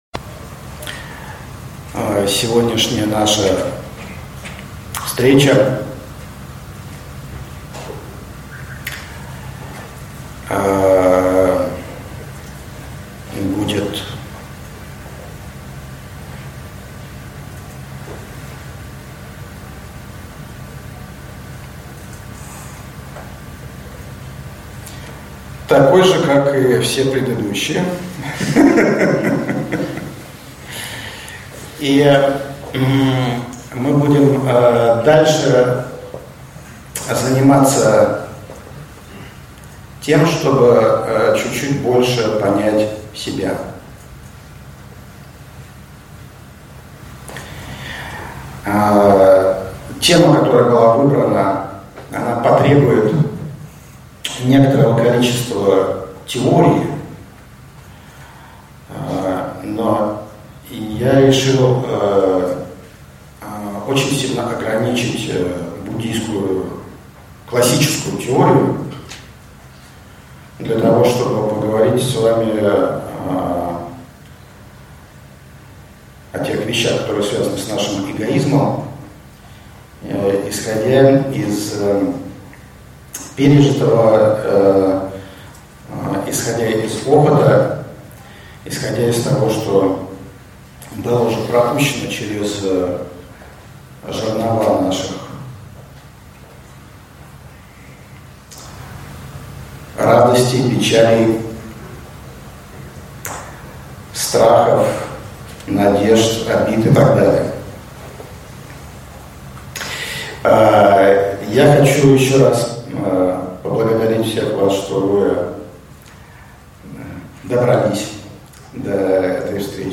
Аудиокнига Трансформация эгоизма | Библиотека аудиокниг